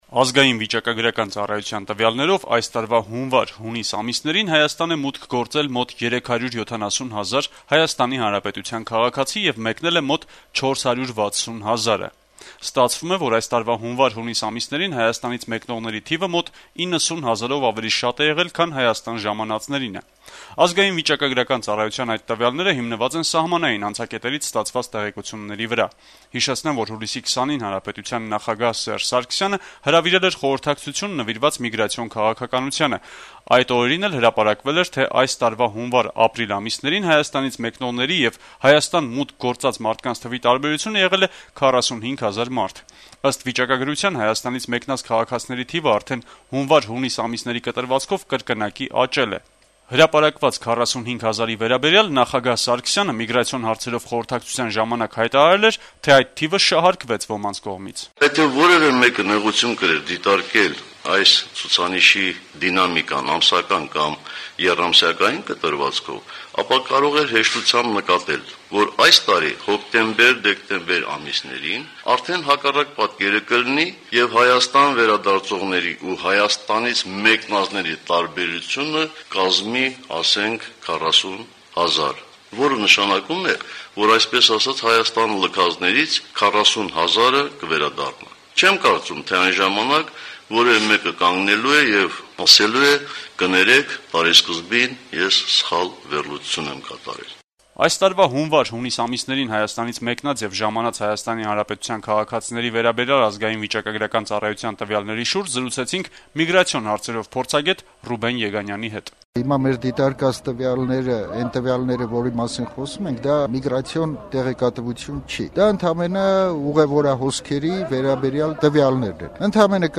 «Ազատություն» ռադիոկայանի հետ զրույցում